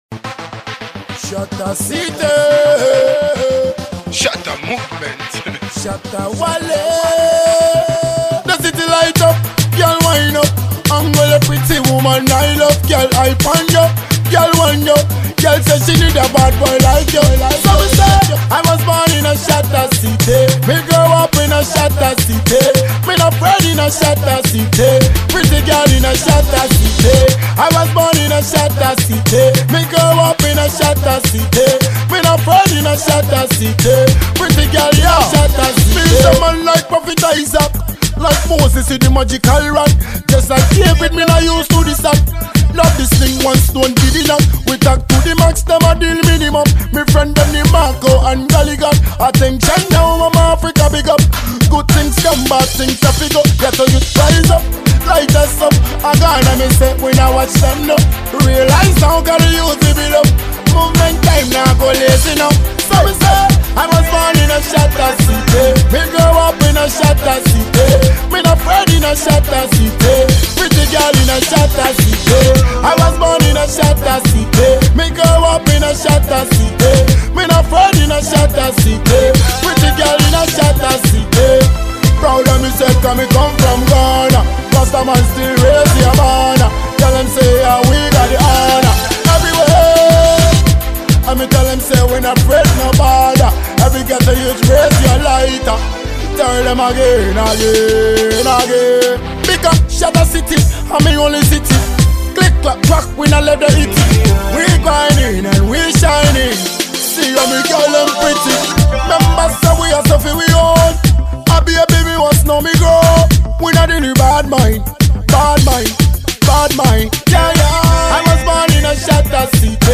dancehall rhythm
Genre: Dancehall